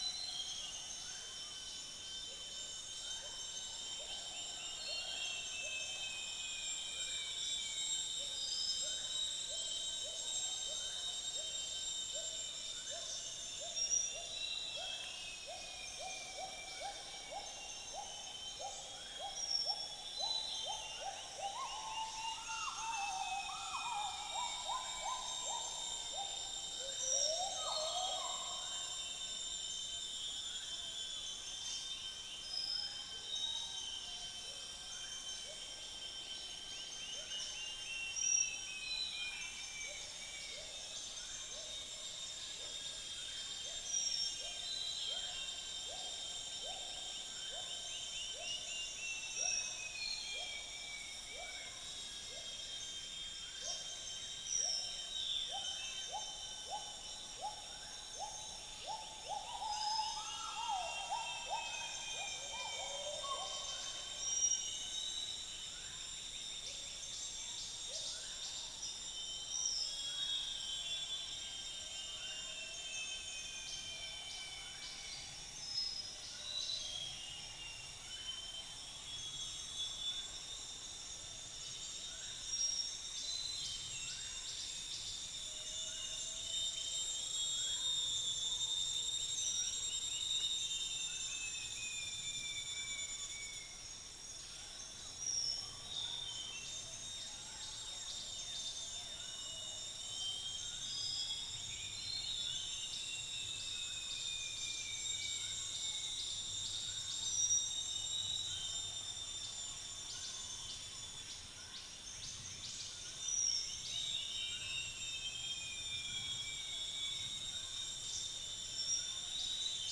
Upland plots dry season 2013
Cyanoderma erythropterum
Trichastoma malaccense
Malacopteron cinereum